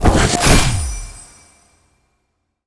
spell_harness_magic_05.wav